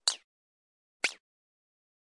太空小说音效 " 05640 晃动射线变换
描述：种类的未来派转换声音 生成
标签： 射线 未来 科幻 变换 效果 过渡 摆动 SFX 激光
声道立体声